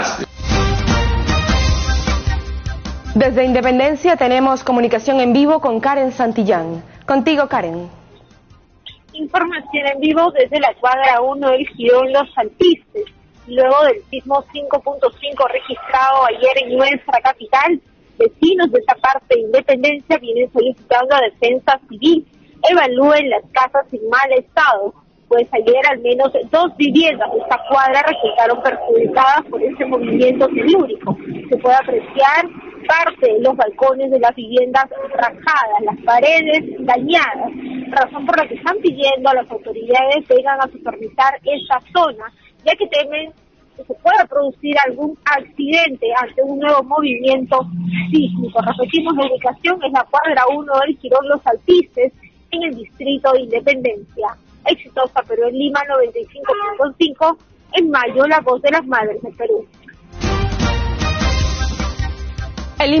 Microinformativo - Exitosa Tv